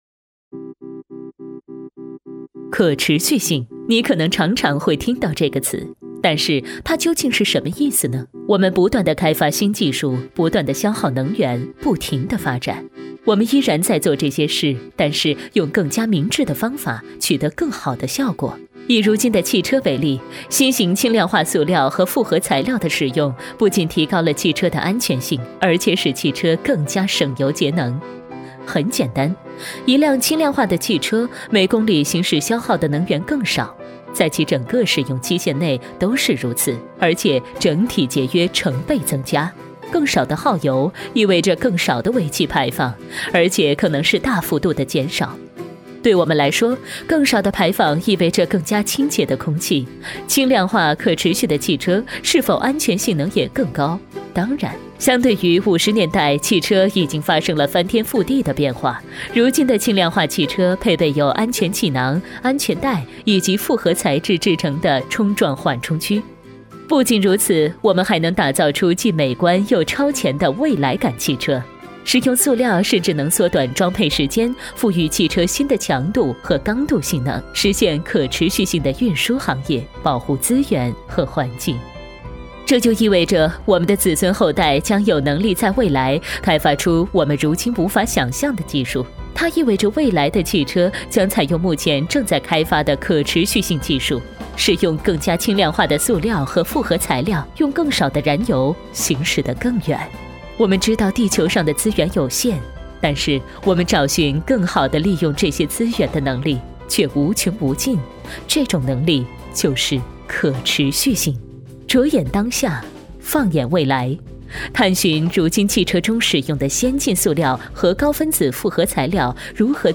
语言：普通话 （44女）
特点：大气浑厚 稳重磁性 激情力度 成熟厚重
风格:大气配音
译制片--可持续轻量化汽车.mp3